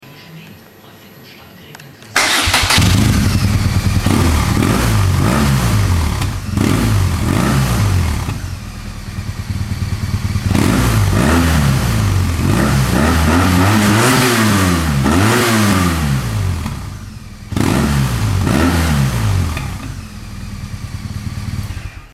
ZACH mit Power DB Killer:
Das neue Rohr mit dem POWER DB Killer - der Sound ist schon sehr satt und kommt schön von unten.
Auspuff mit DB Killer
auspuff_mit_DB.mp3